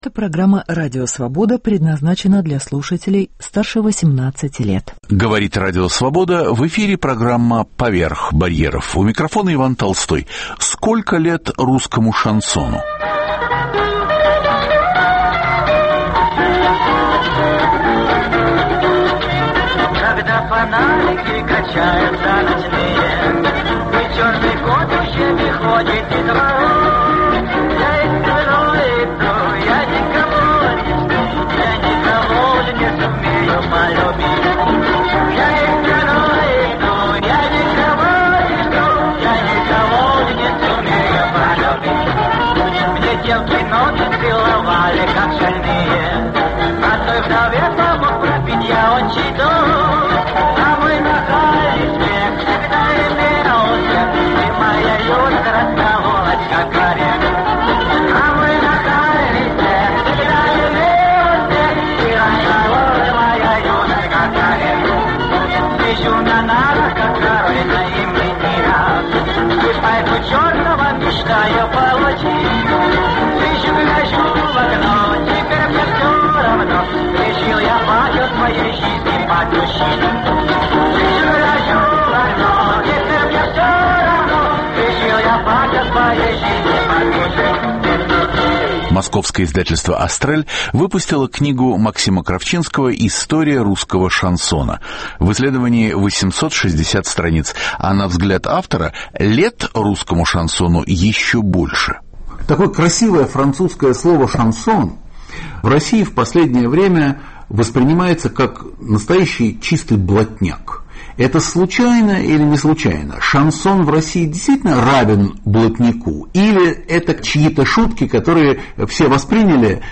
Звучат редкие записи популярных авторов.